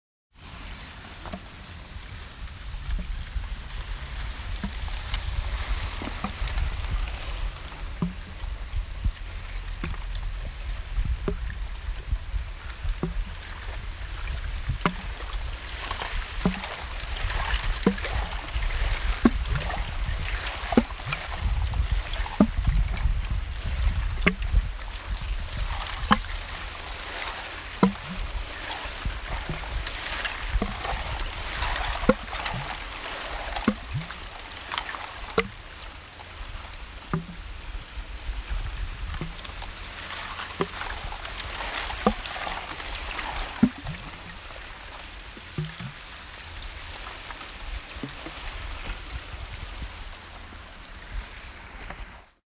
Eisrauschen
In der Zwischenzeit schiebt der Wind auf dem Schweriner See die Eisschollenreste haufenweise ans Ufer: Dünne, teilweise glasklar wirkende Splitter und Platten, die geräuschvoll aneinanderschubbern.
Eisrauschen.mp3